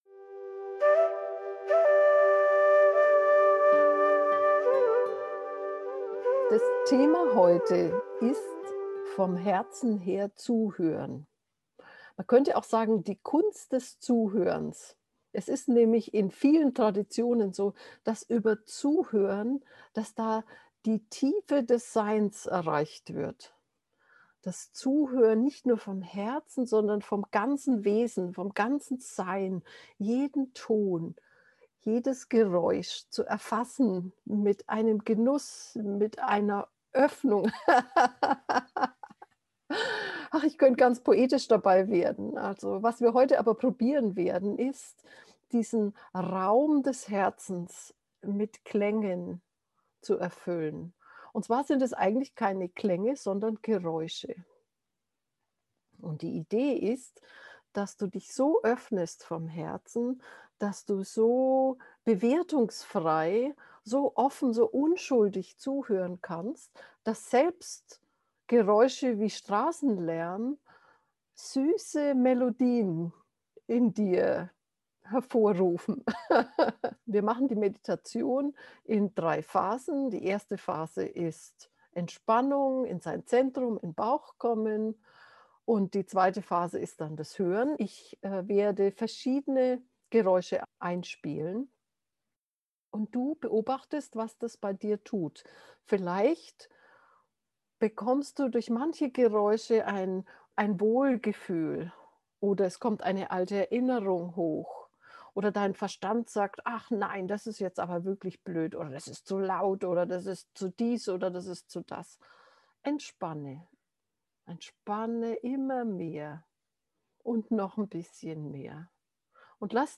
In dieser geführten Meditation entspannst du zuerst in dein Zentrum im Bauch. Dann atmest du sanft und feinfühlig in dein Herz und lässt den Brustraum ausdehnen. Du entspannst noch noch ein bisschen mehr und hörst auf die Geräusche in deiner Umgebung.
vom-herz-hoeren-gefuehrte-meditation